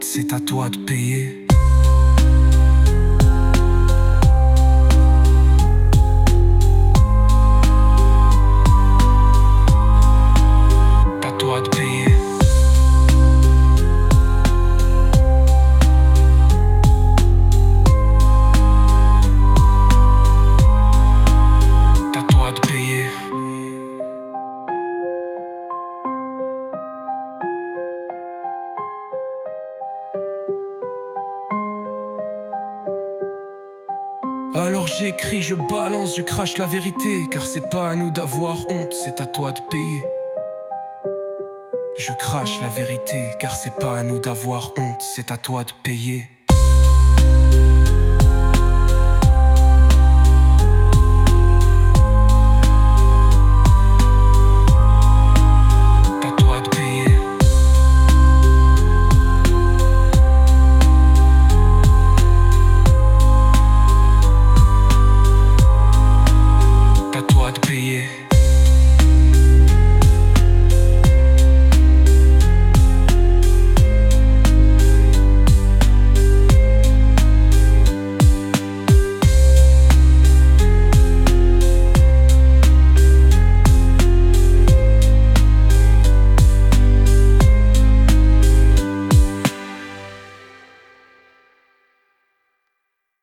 Alternative 3 : « C’est à toi de payer » en boucle